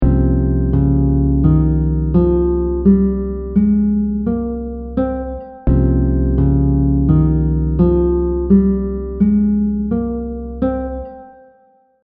The audio examples will repeat the scale over a chord so you can get a better grasp of the sound of each mode.
• Mood / emotion: tense, dark, mysterious 😳
• Characteristic note: flat 2
You can think of the Phrygian scale as a natural minor scale with a flat 2nd degree.
C Phrygian scale audio example